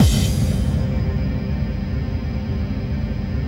DM PAD5-3.wav